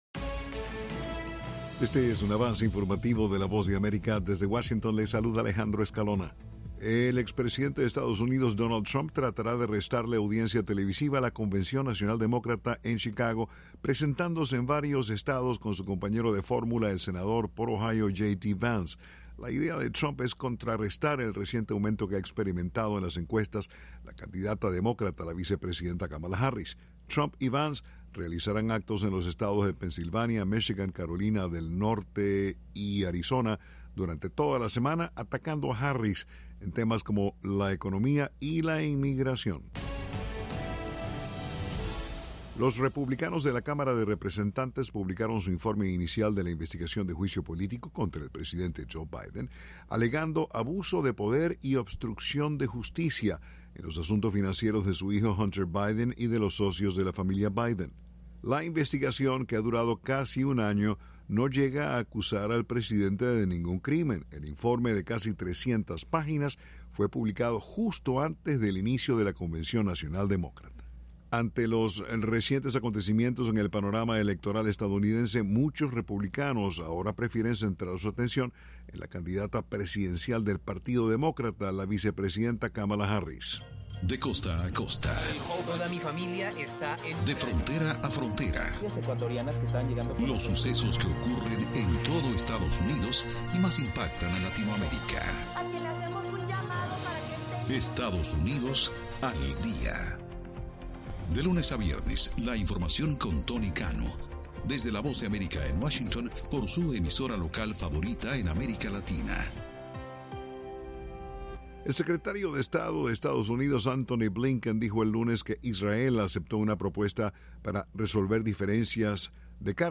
Este es un avance informativo presentado por la Voz de America en Washington.